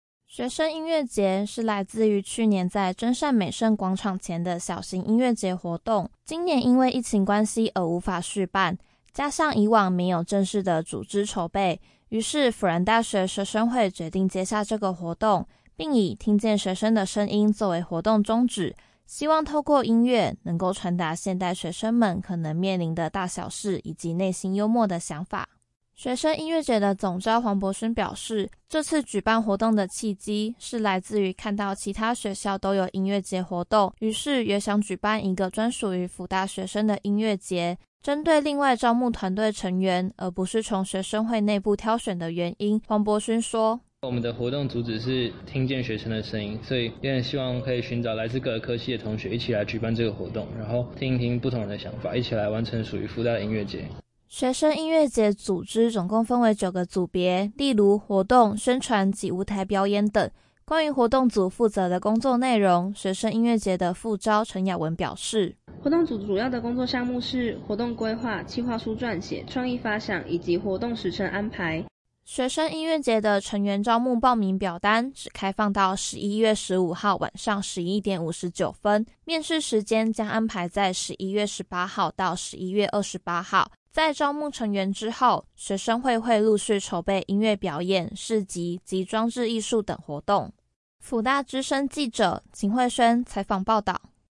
（輔大之聲記者